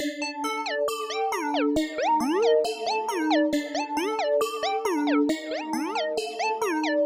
天体循环 136 BPM
描述：简单的合成器循环
Tag: 136 bpm Trap Loops Synth Loops 1.19 MB wav Key : Unknown FL Studio